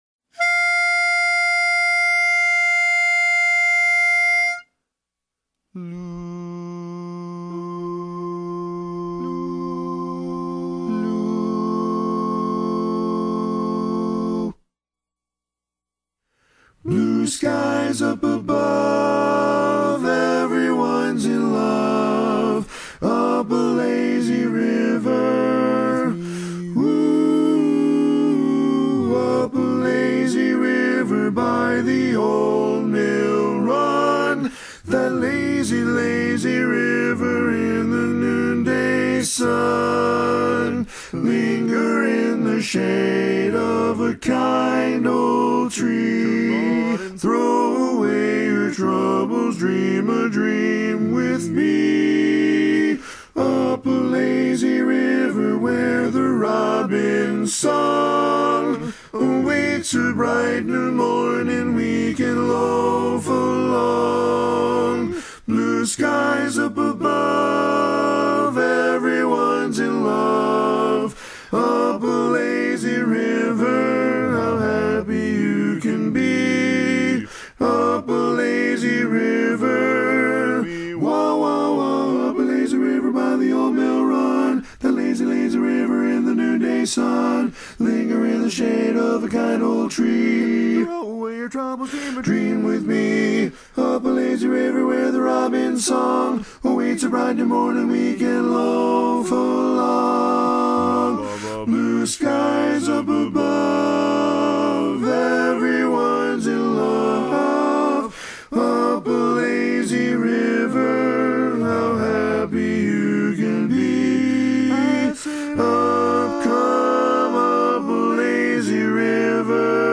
Kanawha Kordsmen (chorus)
Ballad
F Major
Full Mix